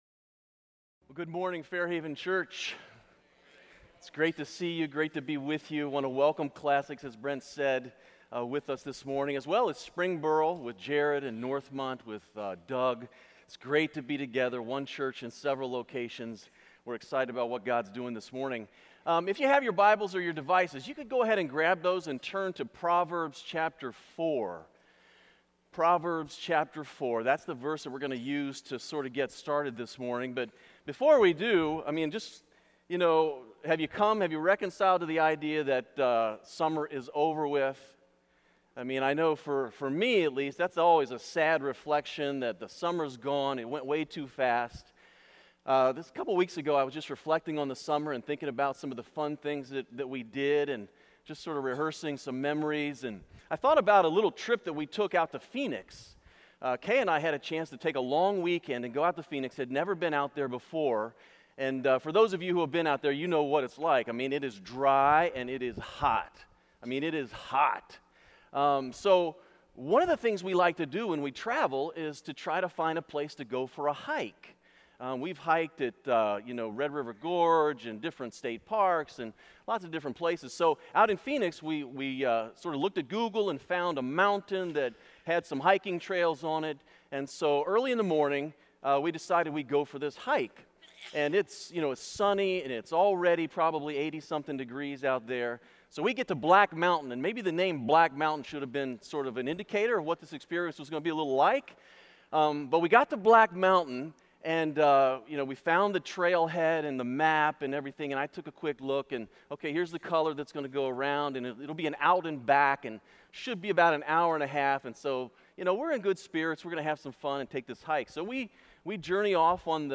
Check out The Essential Journey, a sermon series at Fairhaven Church.